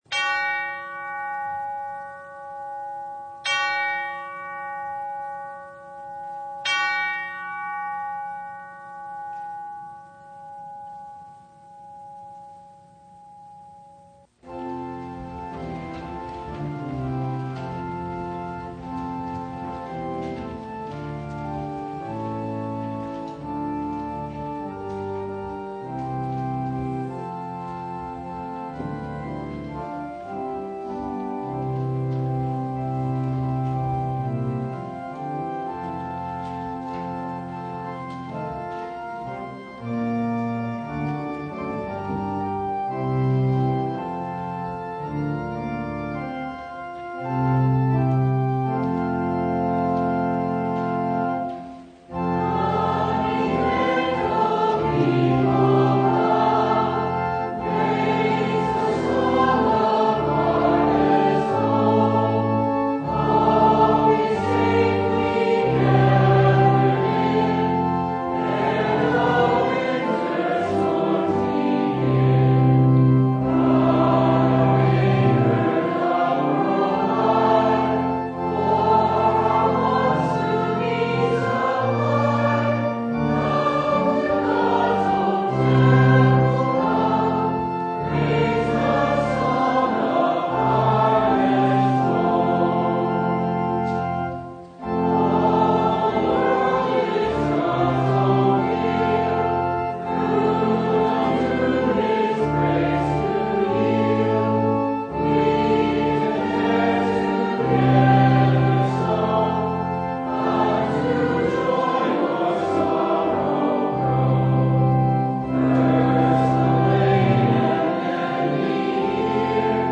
Service Type: Thanksgiving Eve
Download Files Notes Bulletin Topics: Full Service « The Psalms – Psalm 150 No Comparison!